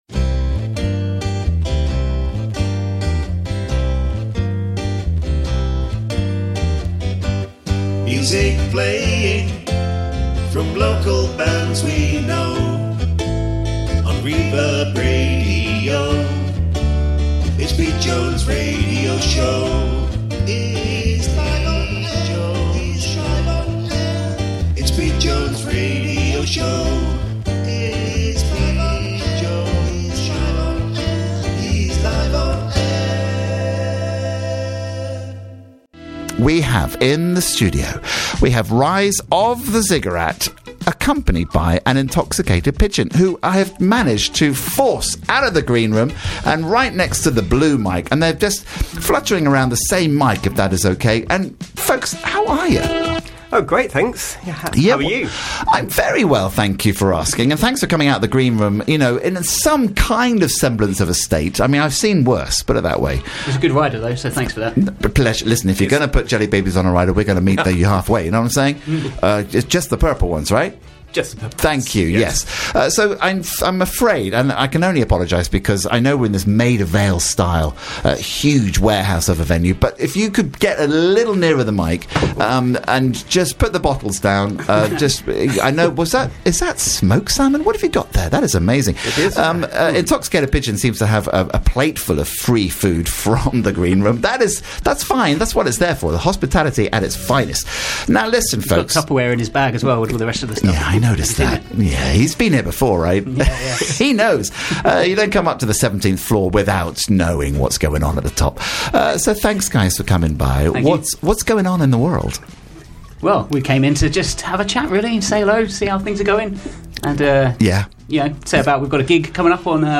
Live chat with Rise of the Ziggurat & Intoxicated Pigeon 4th December 2023